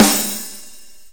normal-hitnormal.wav